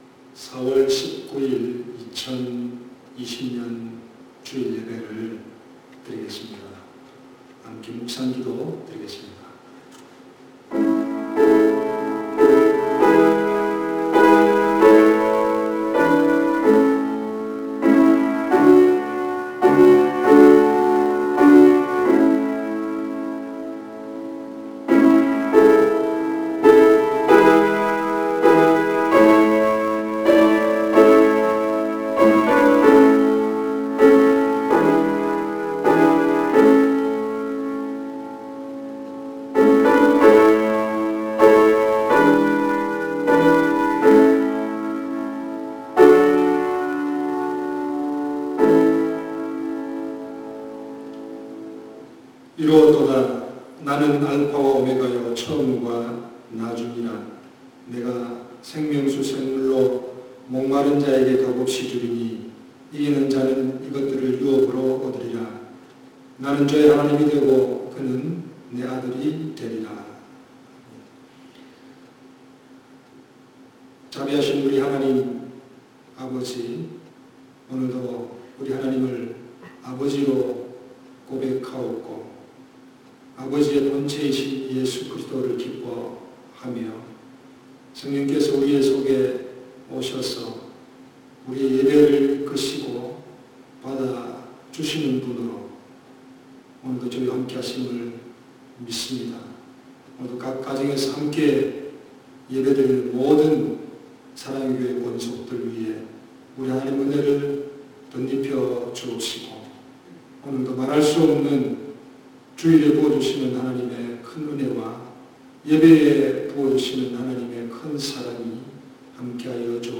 주일 설교